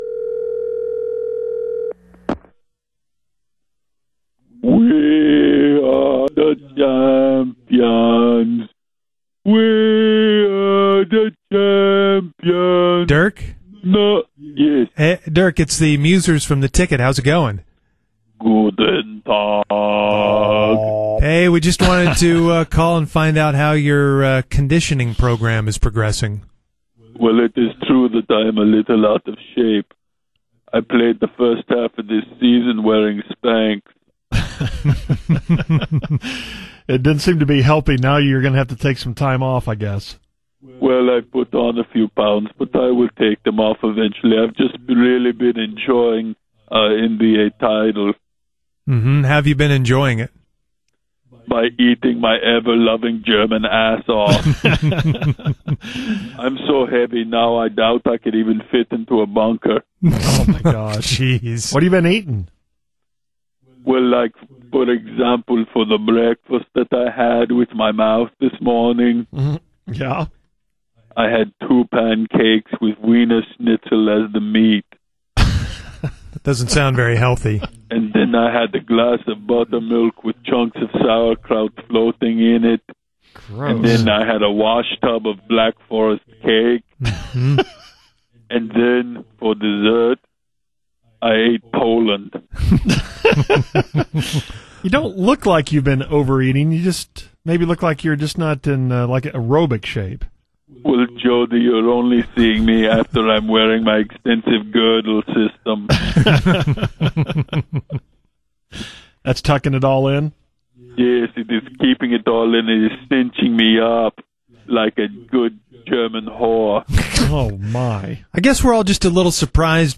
He answers the phone and surprisingly doesn’t sound like he’s trying to get back into shape.